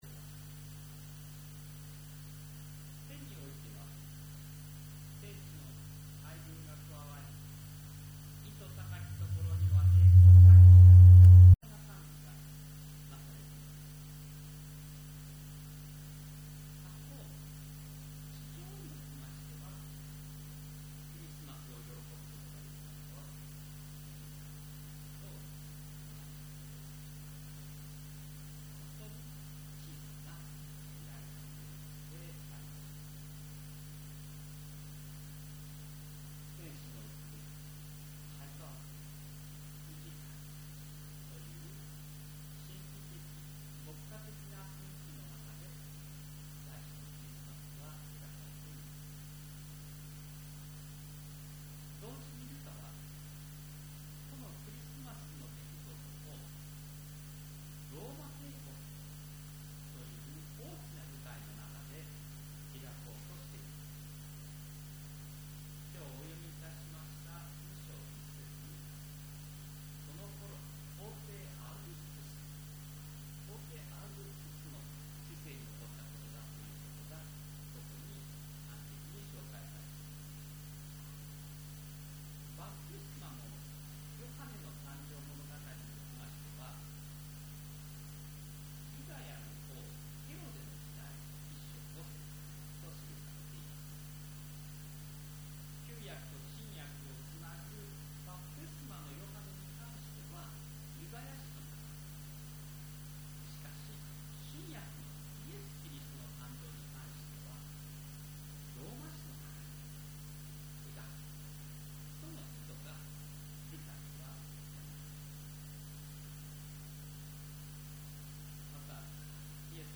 礼拝説教アーカイブ
讃 詠 ５４６番(せいなるかな) 後 奏 (録音状態が非常に低レベルです。